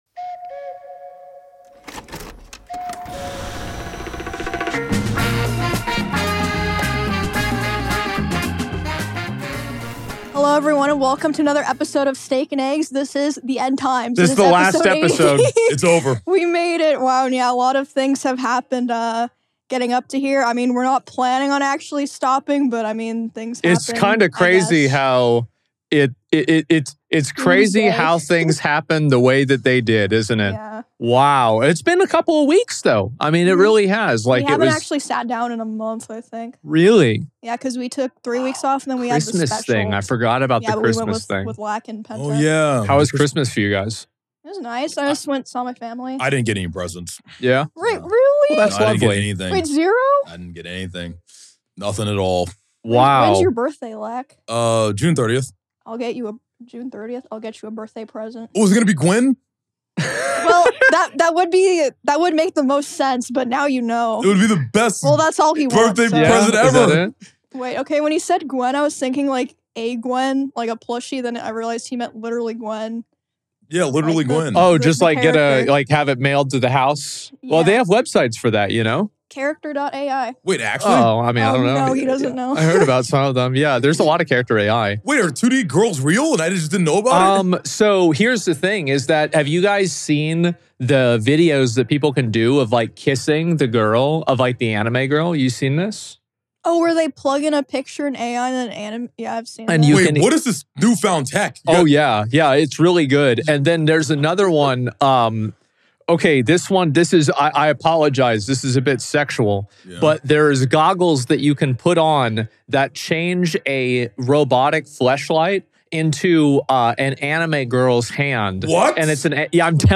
Welcome to Steak & Eggs, a Podcast about gaming, anime, and pop culture 🍳 Hosted by Asmongold and Emiru!